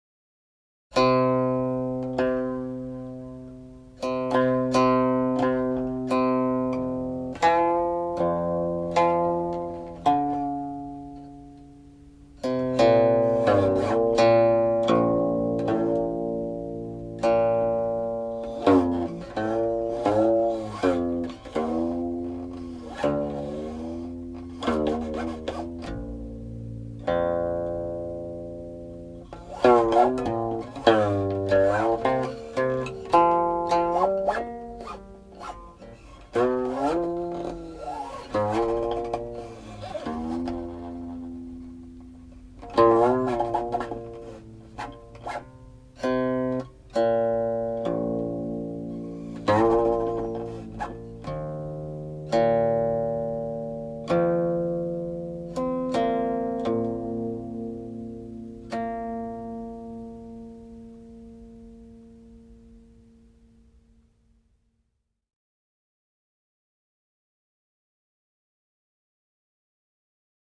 (00.53) -- harmonics
(01.03) -- Modal prelude ends